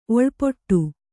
♪ oḷpoṭṭu